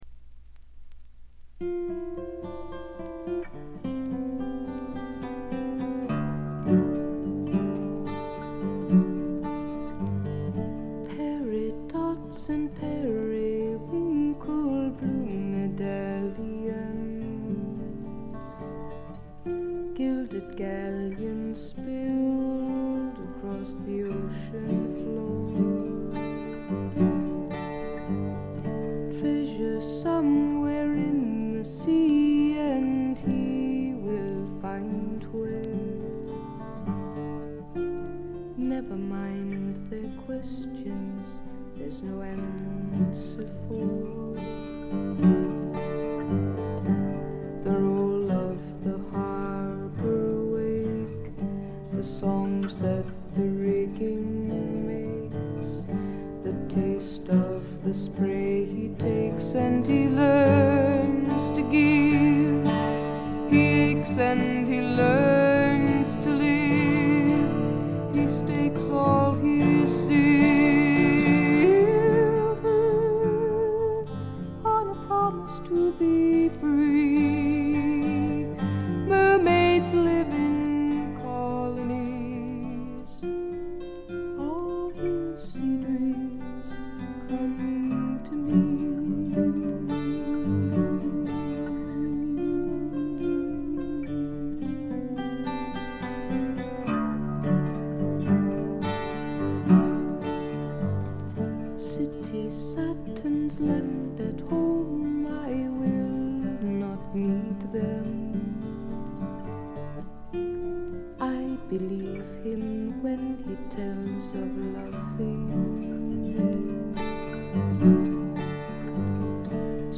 全体にバックグラウンドノイズ。
至上の美しさをたたえたサイケ・フォーク、アシッド・フォークの超絶名盤という見方もできます。
試聴曲は現品からの取り込み音源です。
guitar, piano, vocals